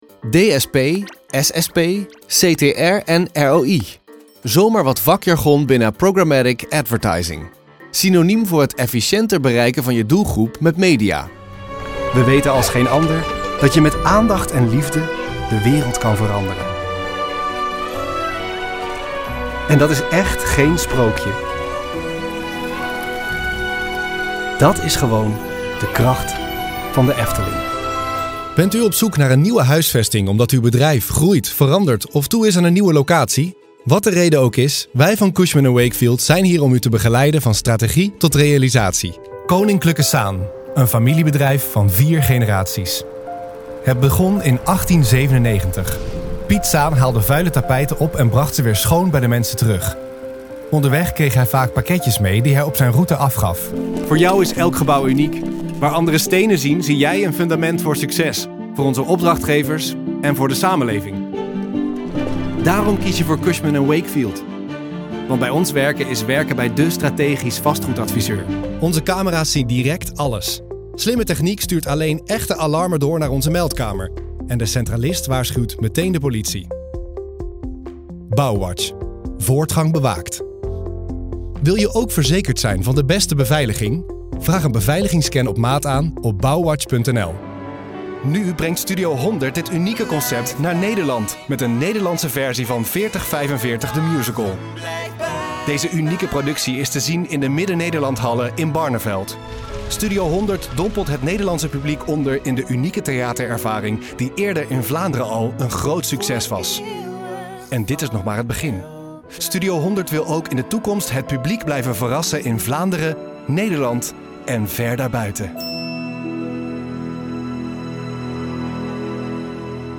Comercial, Versátil, Seguro, Amable, Cálida
Corporativo
Think of a fresh, mature voice with that typical millennial sound: clear, accessible and fresh.